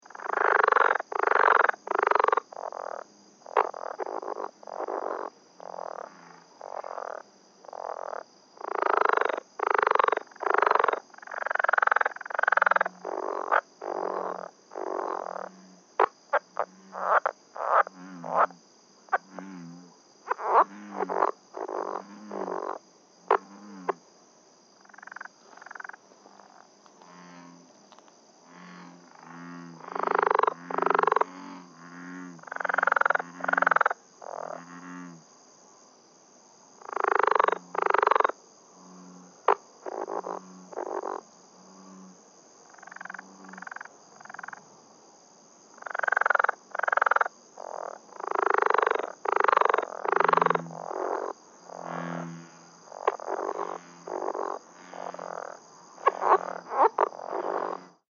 Rio Grande Leopard Frog - Lithobates berlandieri
The advertisement call of the Rio Grande Leopard Frog is a loud, short, low-pitched trill or rattle, lasting less than a second, given singly or in rapid sequences of 2 - 3 trills, made at night.
The following advertisement calls were recorded at night at an irrigation canal in Imperial County (shown below right.)
Sound This is a 58 second recording of the sounds of a group of male frogs. Bullfrogs can be heard in the background.